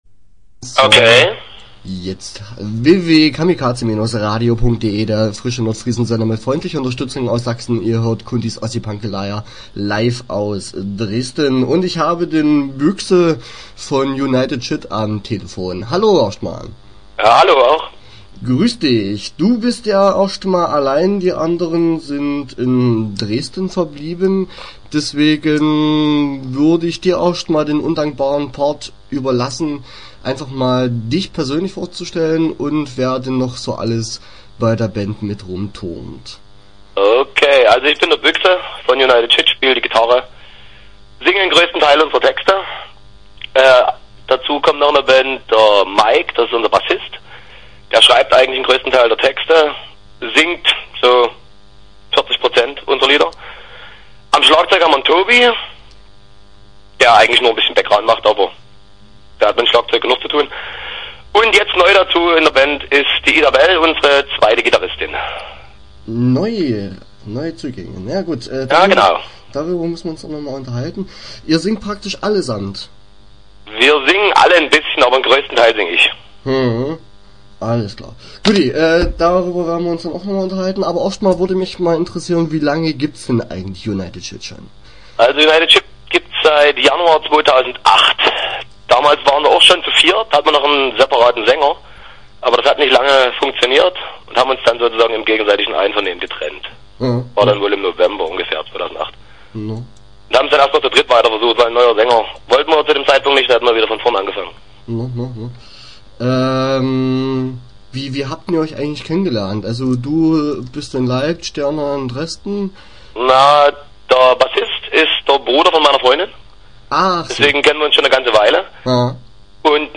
Start » Interviews » United Shit